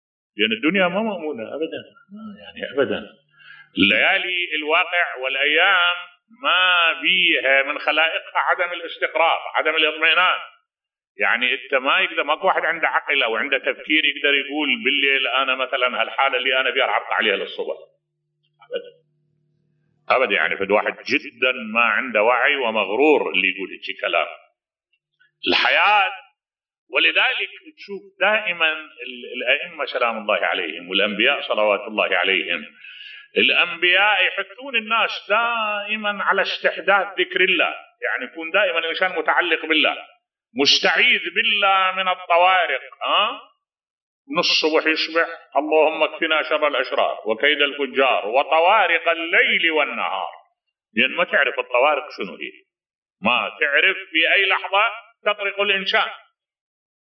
ملف صوتی خلي قلبك دائماً مستحدث بذكر الله بصوت الشيخ الدكتور أحمد الوائلي